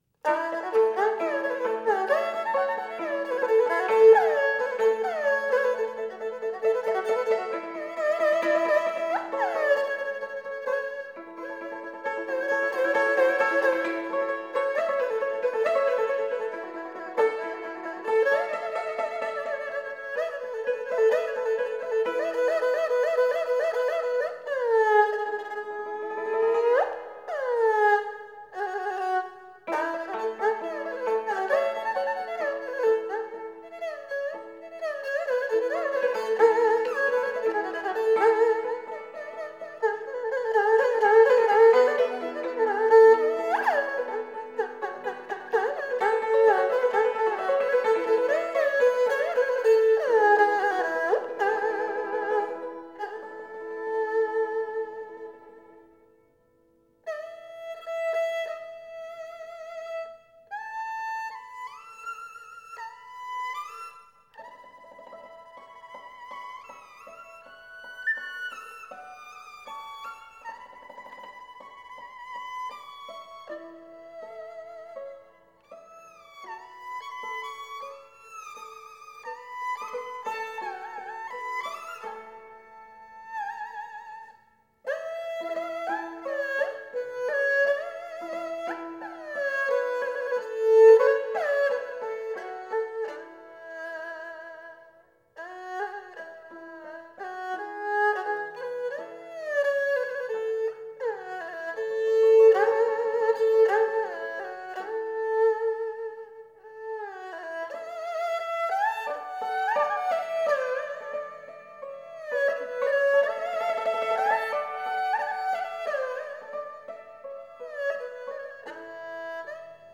0041-京胡名曲夜深沉.mp3